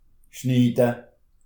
Nous avons tenté d’être représentatifs de cette diversité linguistique en proposant différentes variantes d’alsacien pour chaque lexique, à l’écrit et à l’oral.
La ville d’origine des auteurs (versions écrites) et des locuteurs (versions orales) est précisée en italique.
Illhaeusern